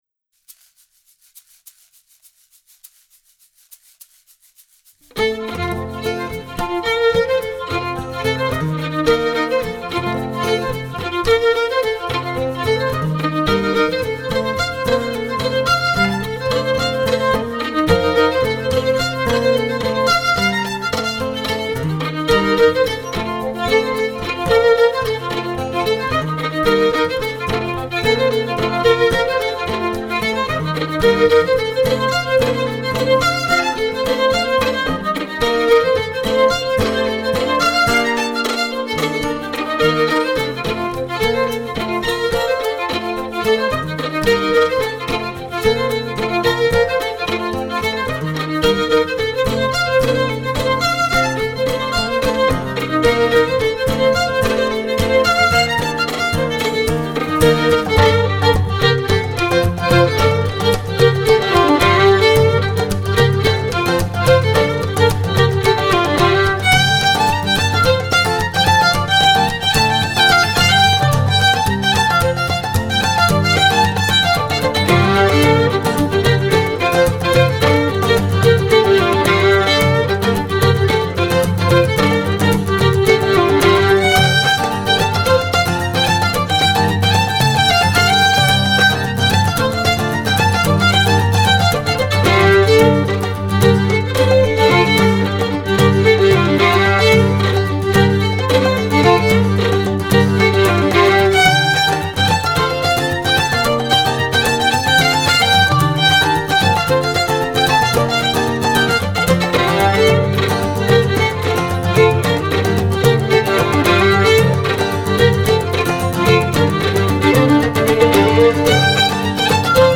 Scottish and Québécois fiddling.
à la guitare
au piano
aux percussions